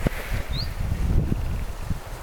hyit-tiltaltti, 2
hyit-tiltaltti.mp3